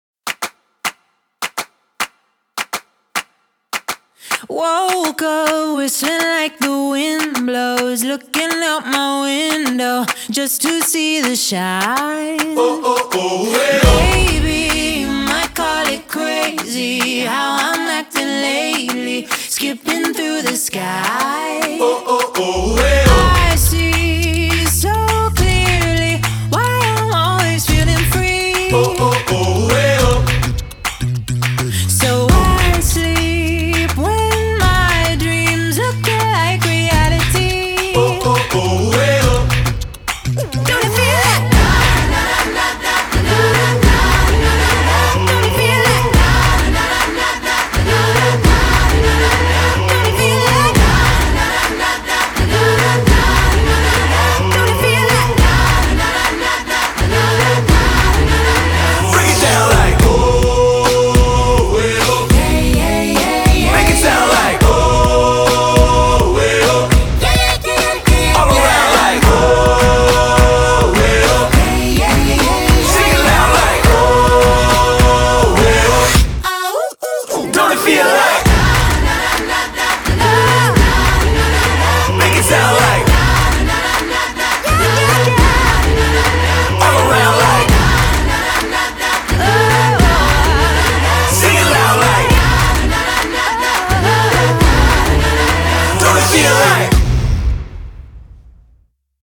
BPM104-208
bursting with energy